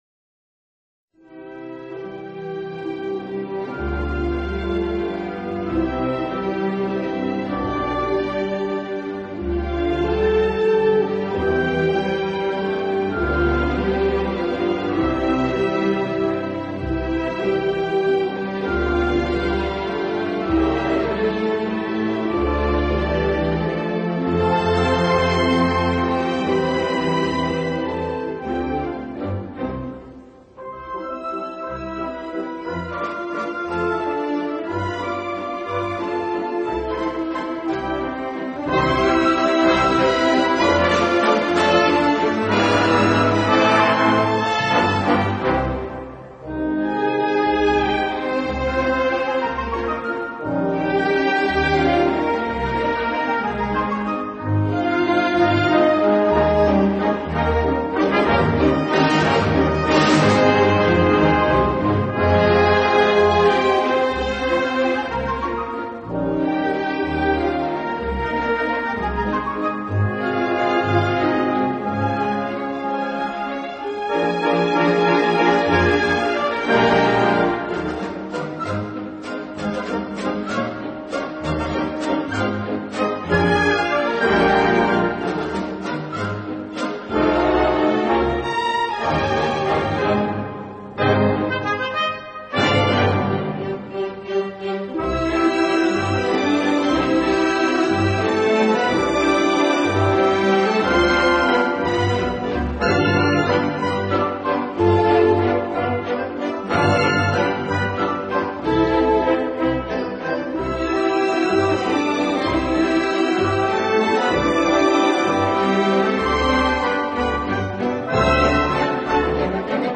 音乐类型：Classic 古典
音乐风格：Classical,Waltz
劳斯乐队的演出方式，自己边拉小提琴边指挥乐队。